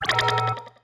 UIBeep_Alert Tremolo.wav